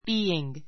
being A2 bíːiŋ ビ ーイン ぐ 動詞 be の-ing形 （現在分詞・動名詞） The house is being built.